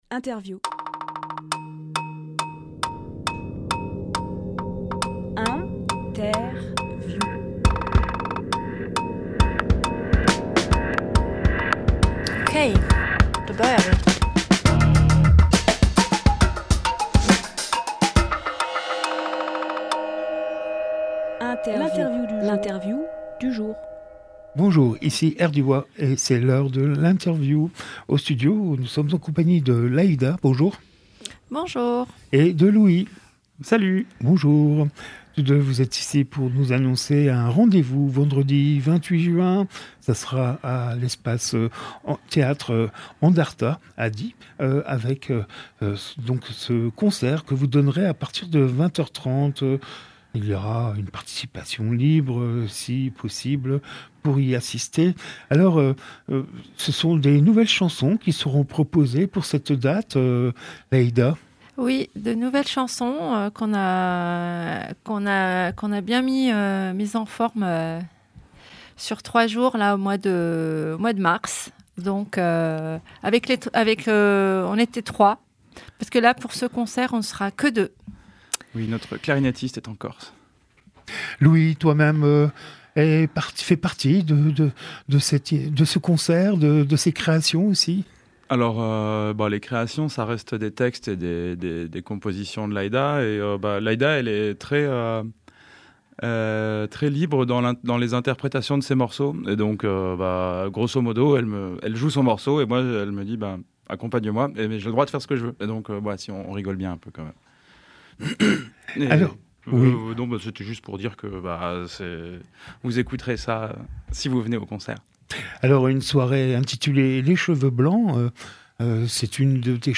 Emission - Interview Les Cheveux Blancs à Andarta Publié le 24 juin 2024 Partager sur…
18.06.24 Lieu : Studio RDWA Durée